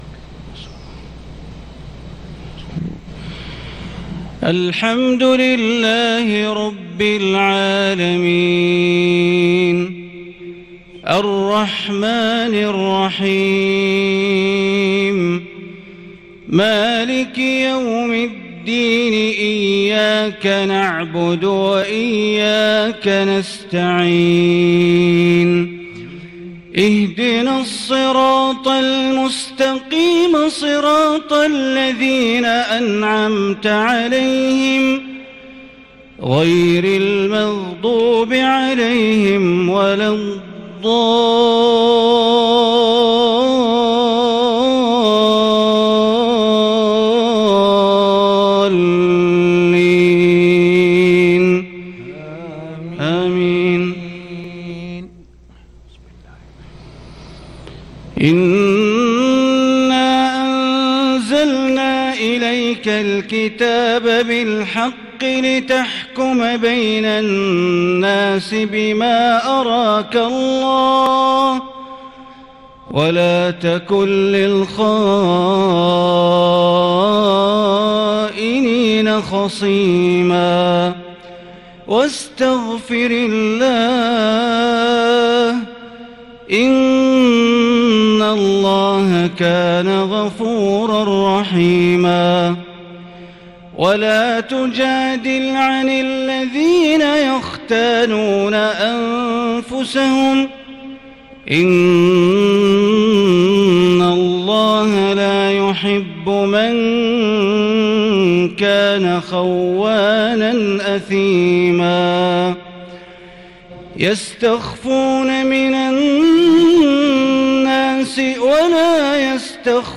صلاة العشاء من سورتي النساء والأحزاب ٢-٥-١٤٤٢هـ | > 1442 هـ > الفروض - تلاوات بندر بليلة